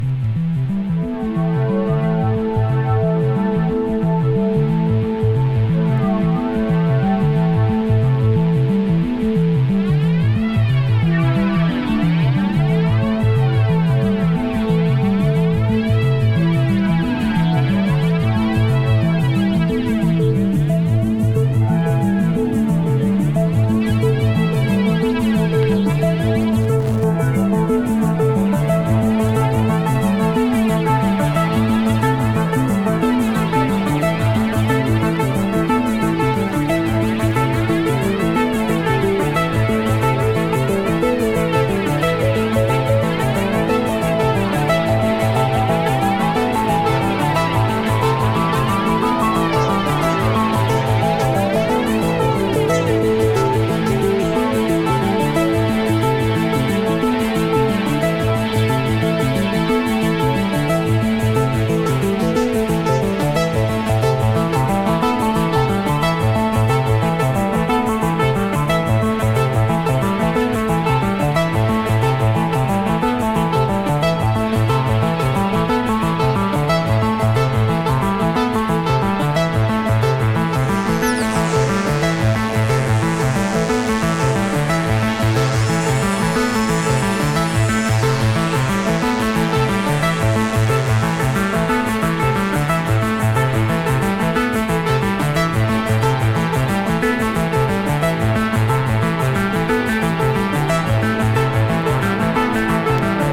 クラウトロック感アリのシンセトラック